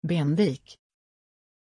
Prononciation de Bendik
pronunciation-bendik-sv.mp3